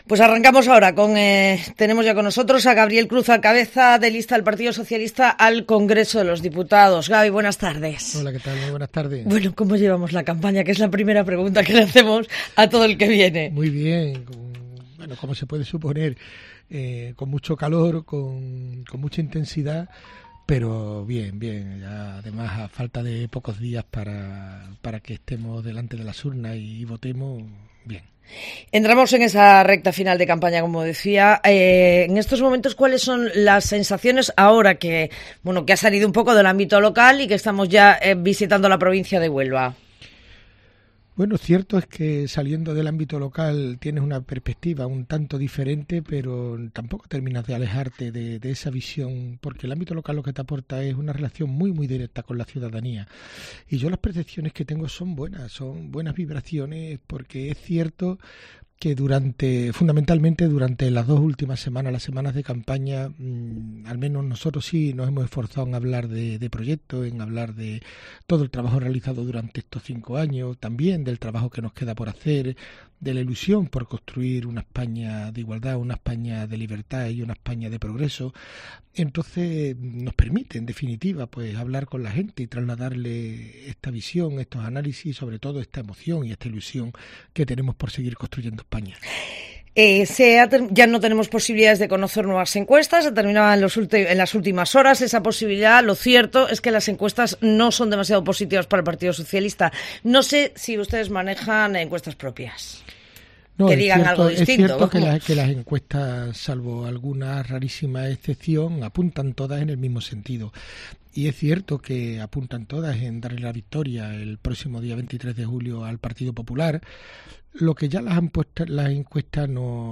Entrevista a Gabriel Cruz, cabeza de lista al Congreso de los Diputados del PSOE